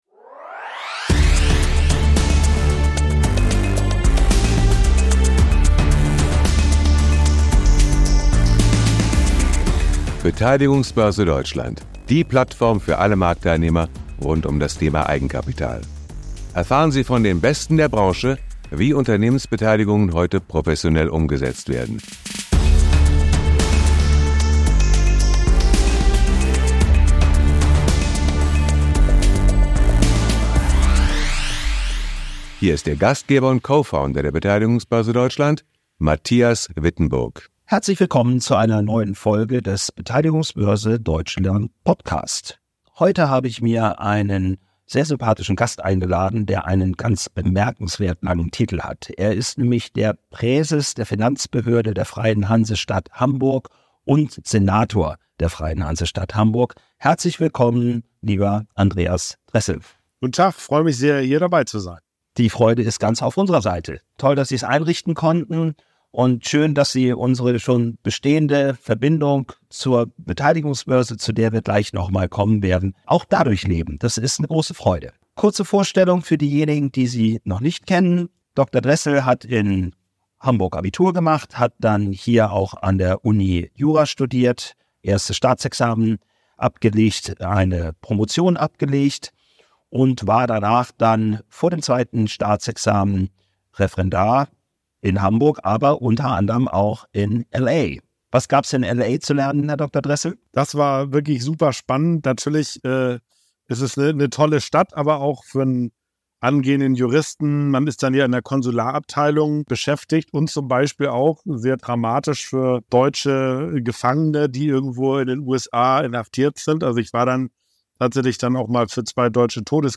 Dr. Andreas Dressel, Finanzsenator der Freien und Hansestadt Hamburg, zu Gast im Beteiligungsbörse Deutschland Podcast ~ Beteiligungsbörse Deutschland Podcast